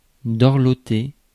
Ääntäminen
Synonyymit soigner entourer gâter chouchouter mignarder mignoter pouponner prendre soin de couver combler chérir cajoler câliner choyer bichonner coucounner prendre soin Ääntäminen France: IPA: /dɔʁ.lɔ.te/